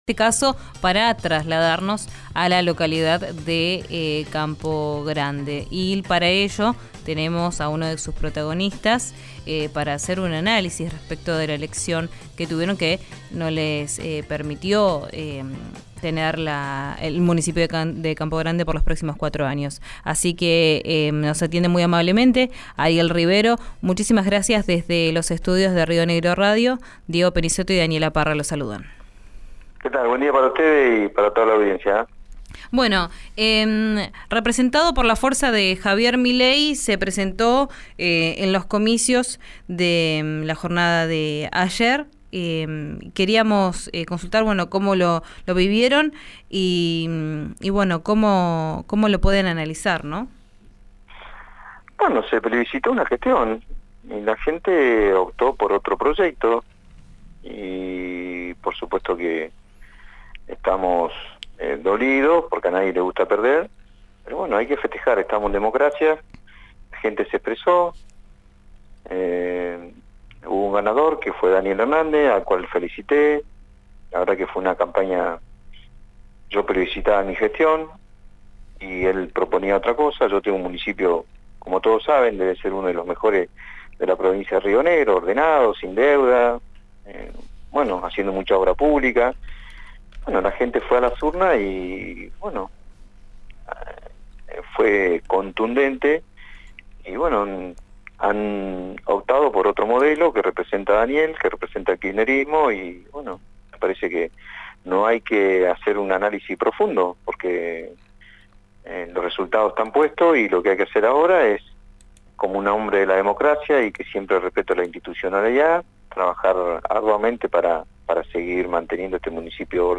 En diálogo con Río Negro RADIO analizó la derrota.
Escuchá al actual intendente de Campo Grande, Ariel Rivero, en RÍO NEGRO RADIO: